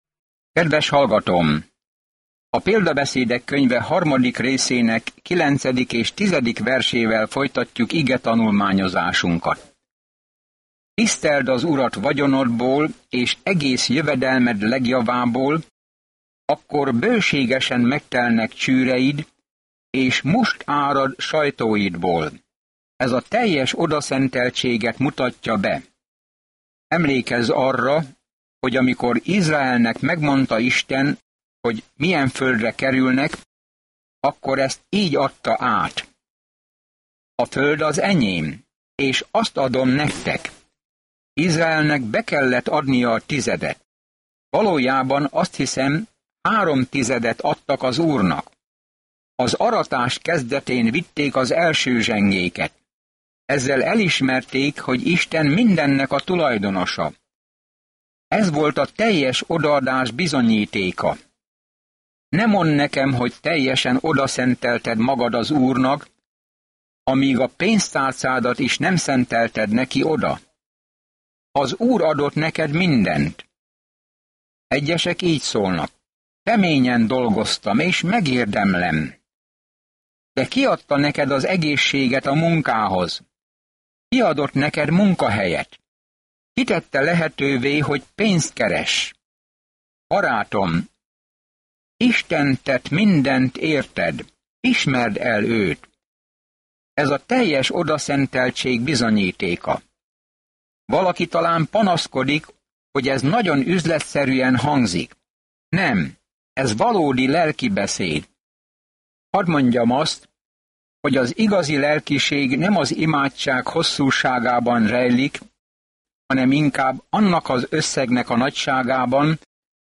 Szentírás Példabeszédek 3:9-35 Példabeszédek 4:1-4 Nap 4 Olvasóterv elkezdése Nap 6 A tervről A közmondások hosszú tapasztalatokból merített rövid mondatok, amelyek könnyen megjegyezhető módon tanítják az igazságot – olyan igazságok, amelyek segítenek bölcs döntéseket hozni. Napi utazás az Példabeszédek, miközben hallgatja a hangos tanulmányt, és olvassa el Isten szavának kiválasztott verseit.